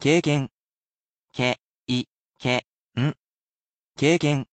I also read the word for you aloud. I sound it out, and you can repeat after me.